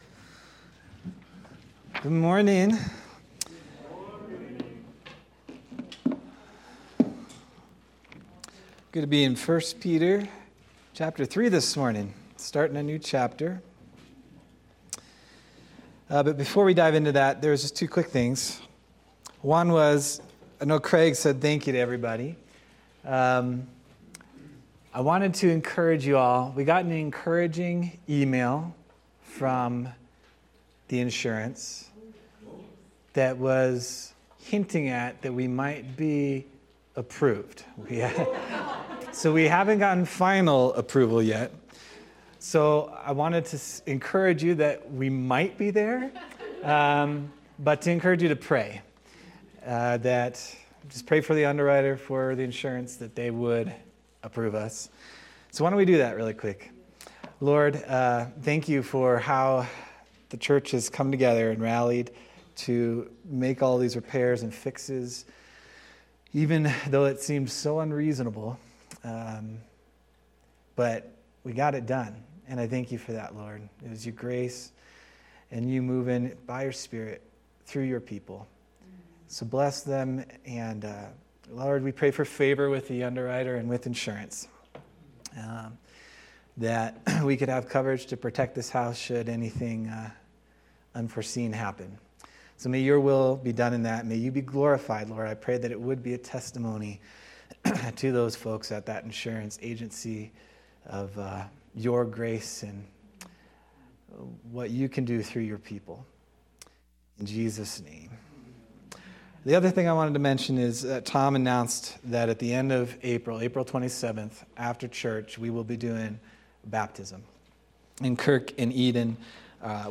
April 6th, 2025 Sermon